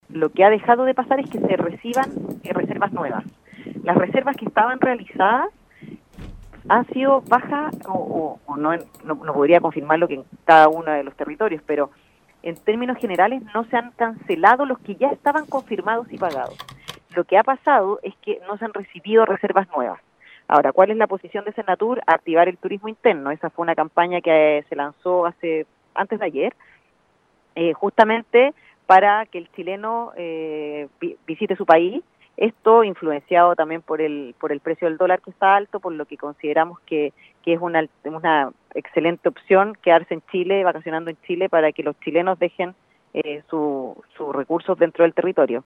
En el marco de su visita por la provincia de Palena, la Directora del Servicio Nacional de Turismo en la Región de Los Lagos, Paulina Ros, conversó con Radio Estrella del Mar, oportunidad en que señaló que el objetivo fue poder reunirse con actores ligados a la industria turística.